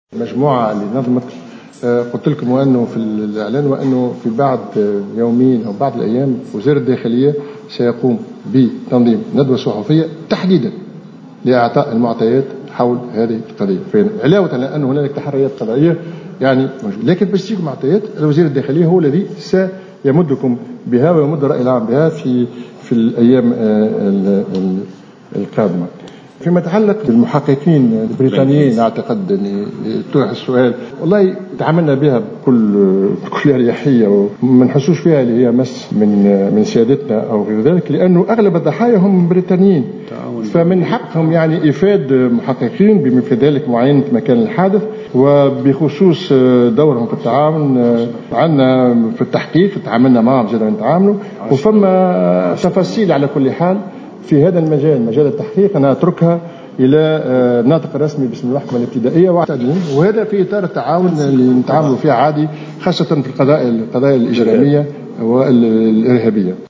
أكد الوزير لدى رئيس الحكومة المكلف بالعلاقة مع الهيئات الدستورية والمجتمع المدني كمال الجندوبي خلال ندوة صحفية عقدت اليوم الخميس 02 جويلية 2015 بقصر الحكومة بالقصبة للإدلاء بالمعطيات المتوفرة لدى خلية الاتصال حول العملية الارهابية بسوسة أن عدد المحققين البريطانيين 10 و ليس 600 محقق كما يروج له.